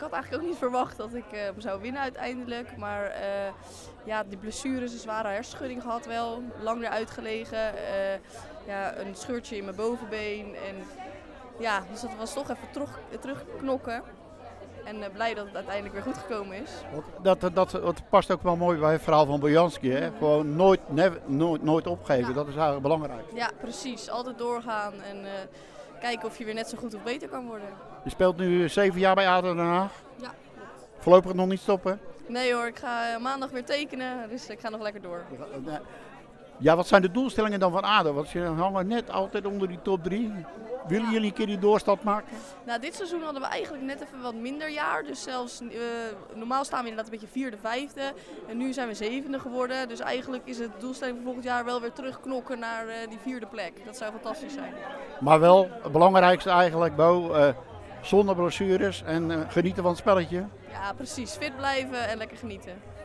Teylingen – Tijdens het Sportgala Teylingen zijn donderdagavond de jaarlijkse sportprijzen uitgereikt.